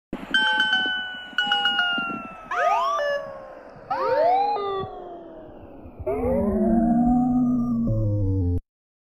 Phone Shutting Off Meme Sound Button: Unblocked Meme Soundboard